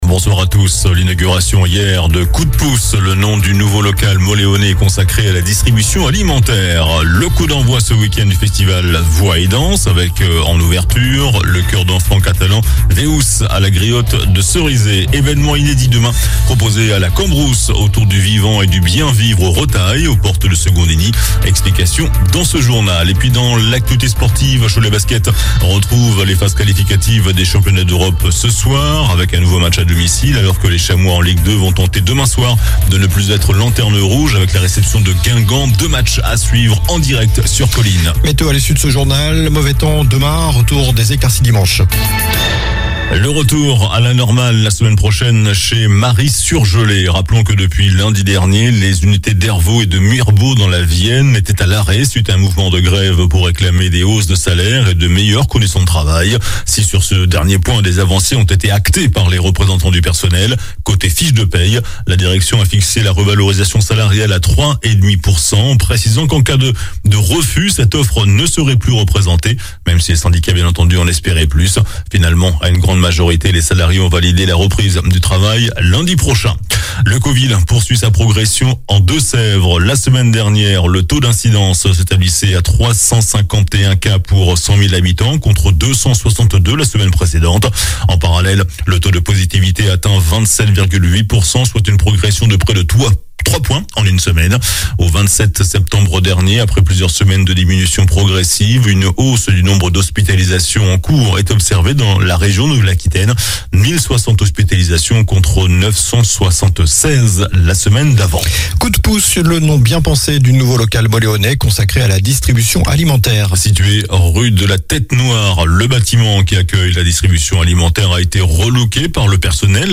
JOURNAL DU VENDREDI 30 SEPTEMBRE ( SOIR )